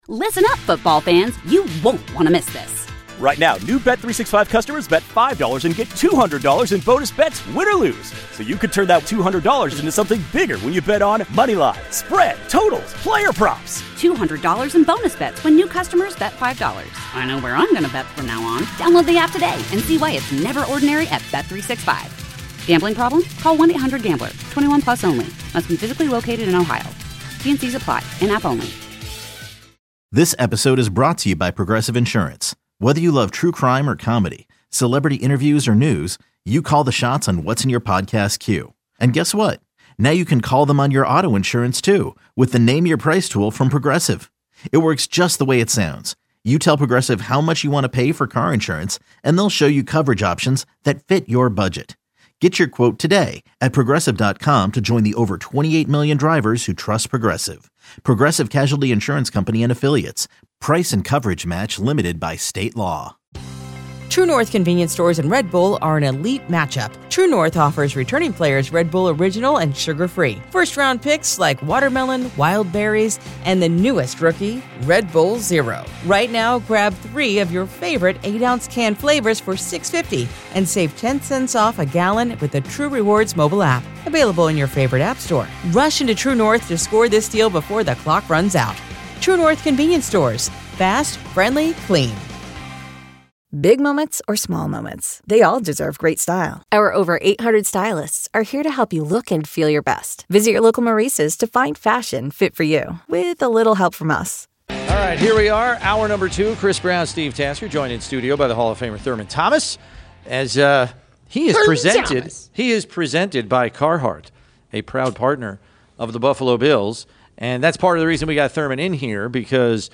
Best of WGR Interviews: August 25 - August 29